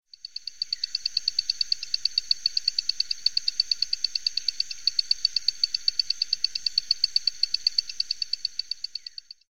19 Epipedobates Hahneli.mp3